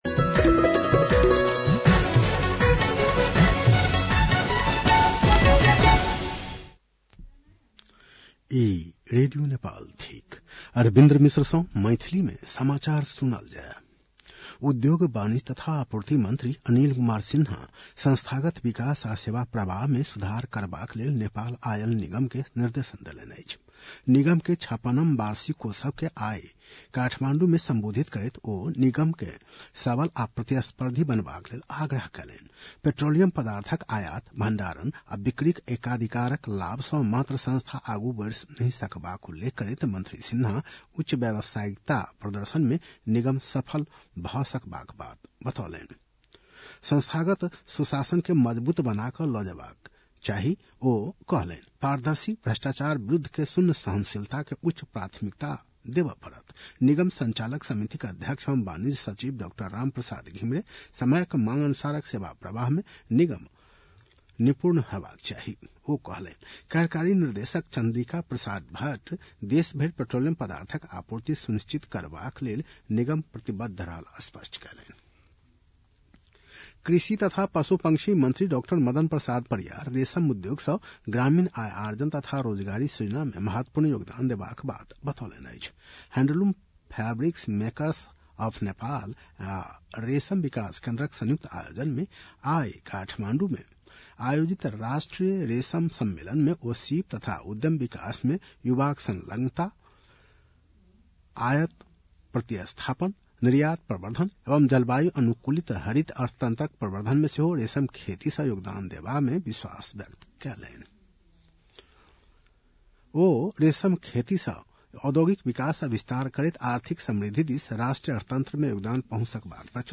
मैथिली भाषामा समाचार : २६ पुष , २०८२